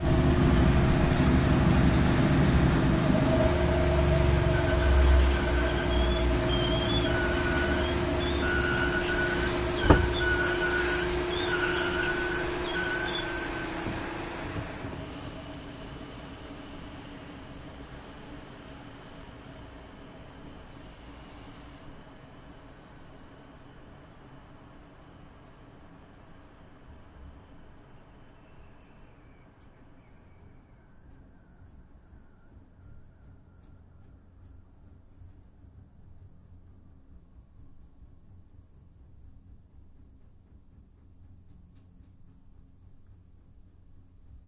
风琴，教堂，气动系统，引擎，运行，停止，特写 立体声预览
描述：器官，教堂，气动系统，引擎，运行，停止，特写镜头 MS录音的立体声混音，使用Sennheiser 416（M）和8 AKG的一些小振膜。
标签： 教堂 器官 机械 气动
声道立体声